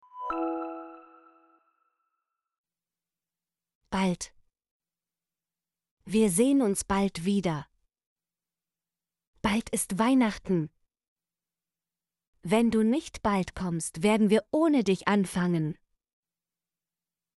bald - Example Sentences & Pronunciation, German Frequency List